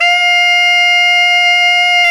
Index of /90_sSampleCDs/Roland LCDP07 Super Sax/SAX_Tenor mf&ff/SAX_Tenor ff
SAX TENORF0X.wav